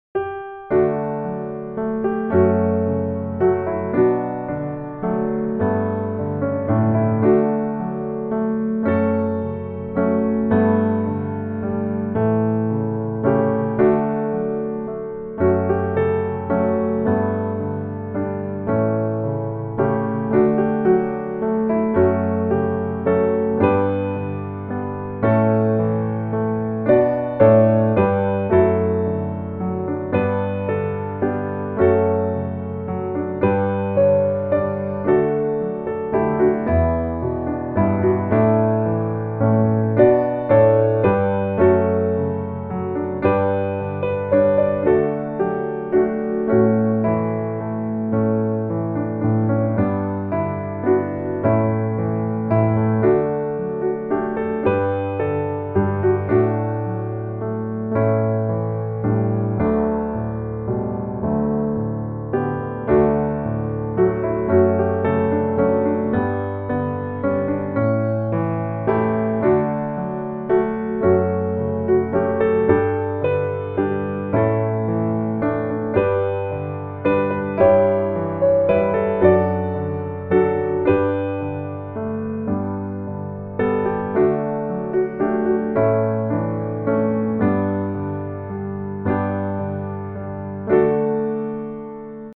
D大調